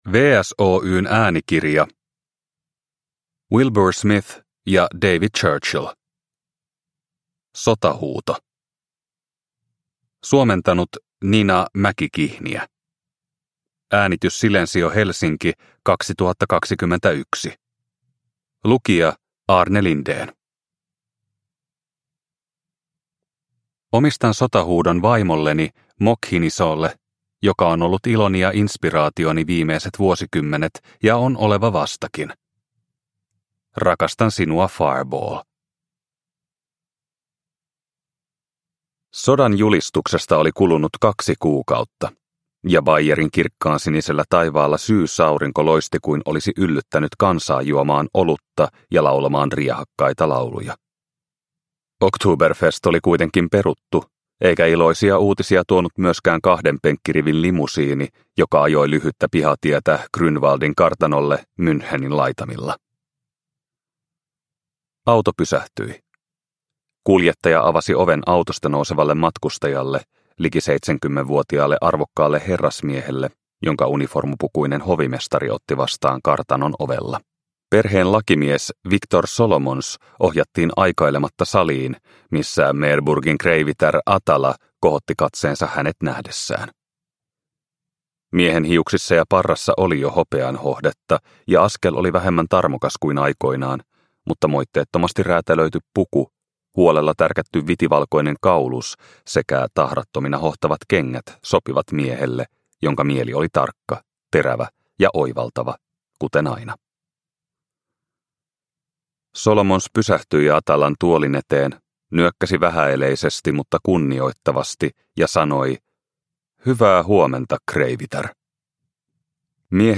Sotahuuto – Ljudbok – Laddas ner